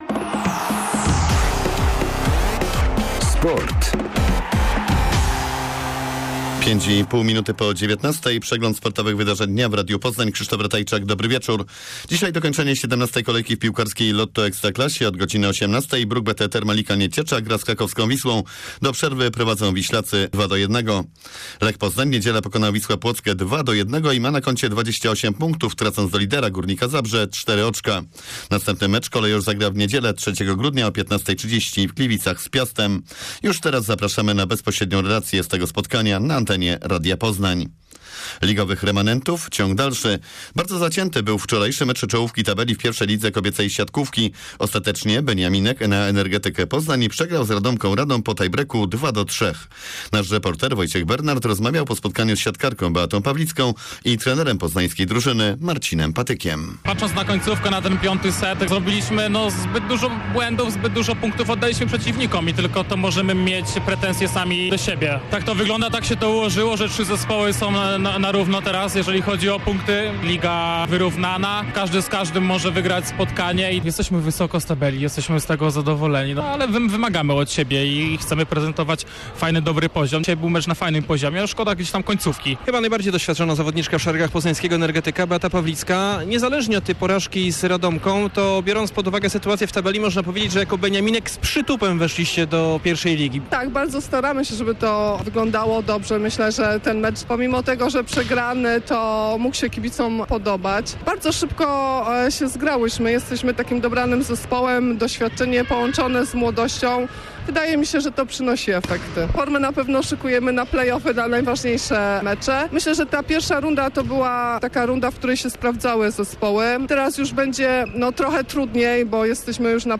27.11 serwis sportowy godz. 19:05